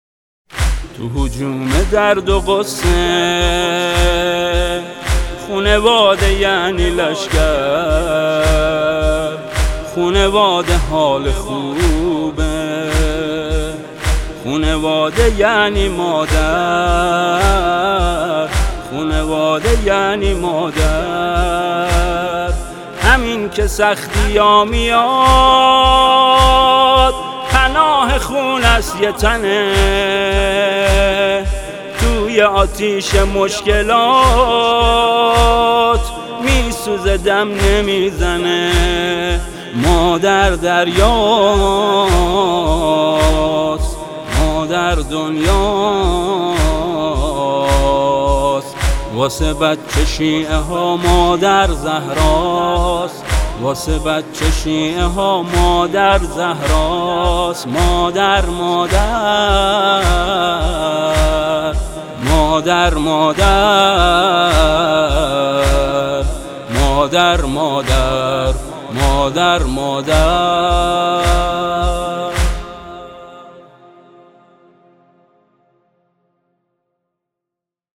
اثر برگزیده کنگره ملی نوحه